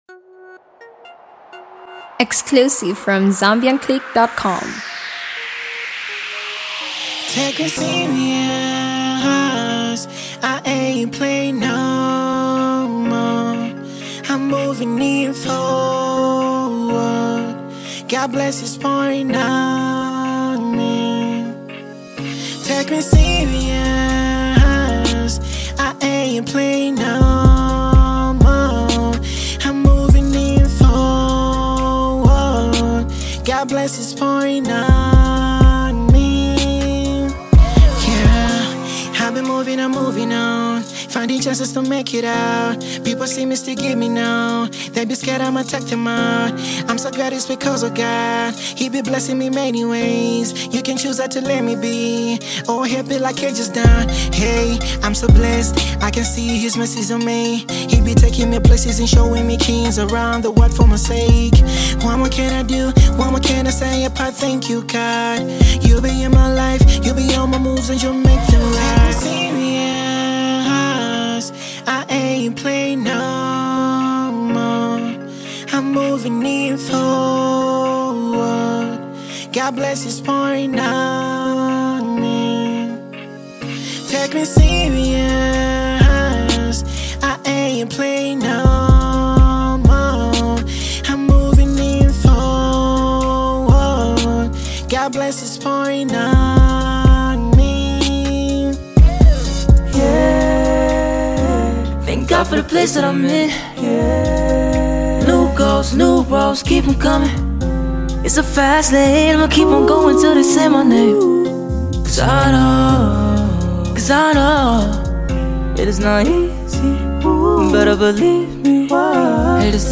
new wave
an American multi-talented Guitarist, Actor, and Singer